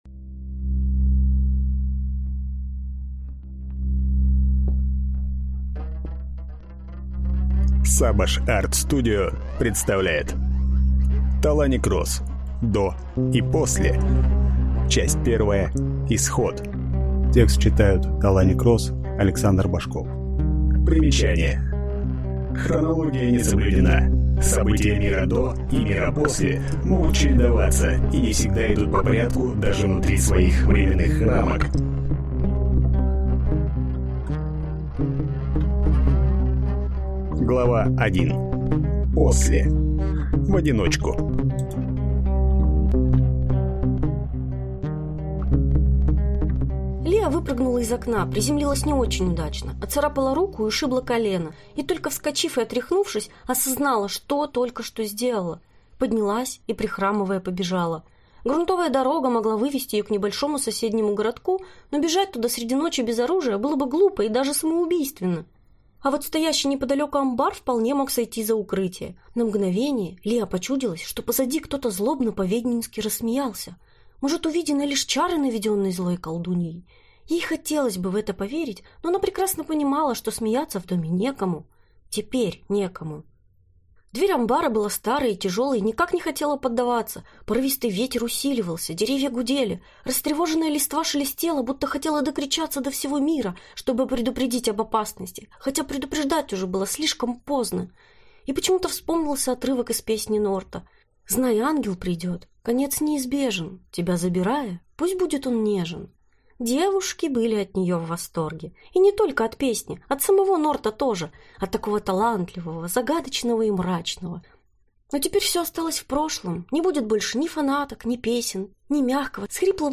Aудиокнига До и После.